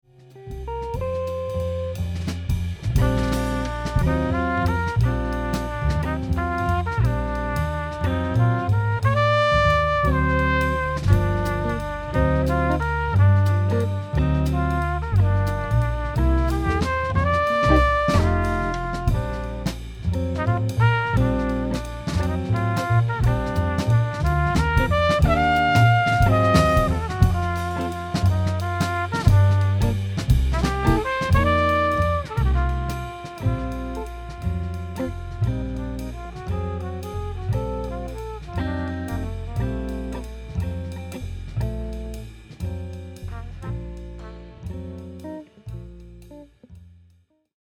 (en quartet : trompette, guitare, contrebasse, batterie) :
Balade -